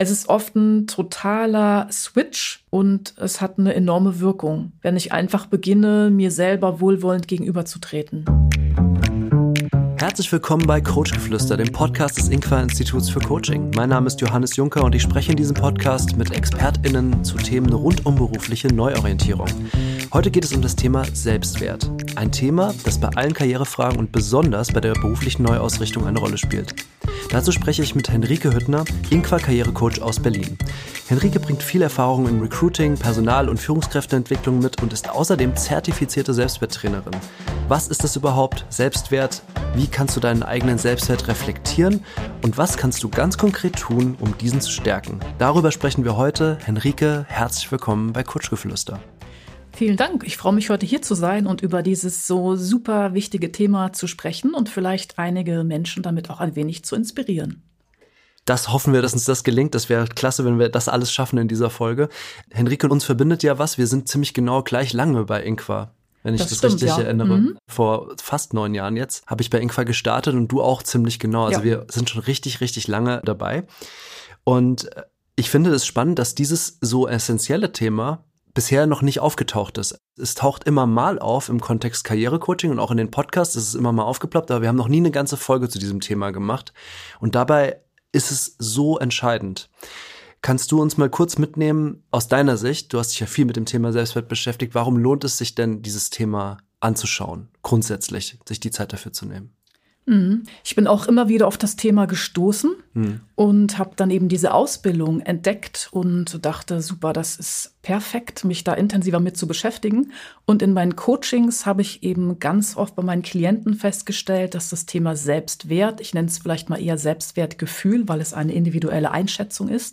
Gemeinsam sprechen die beiden über persönliche Erfahrungen, typische Situationen aus dem Karriere-Coaching und darüber, wie du deine inneren Muster neu anschauen kannst. Eine Folge, die dich einlädt, dir selbst mit mehr Freundlichkeit zu begegnen und dabei neue Spielräume für deine berufliche Entwicklung zu entdecken.